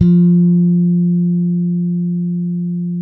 -MM JAZZ F 4.wav